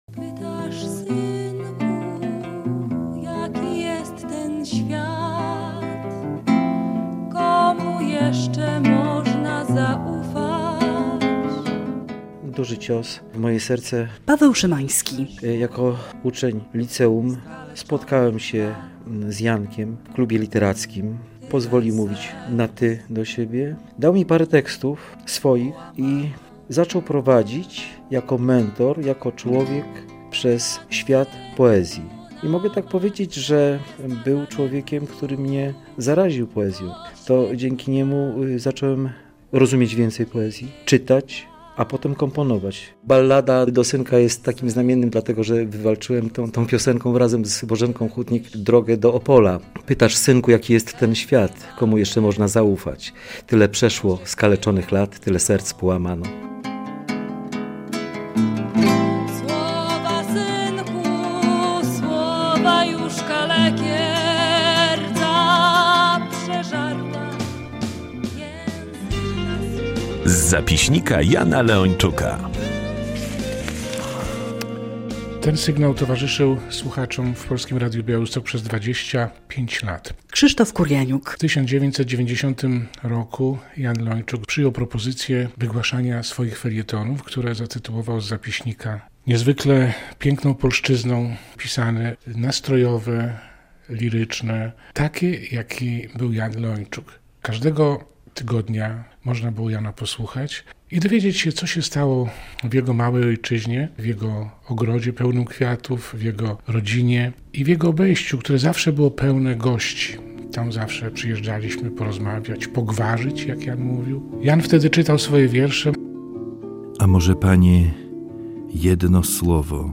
Jan Leończuk we wspomnieniach znajomych - relacja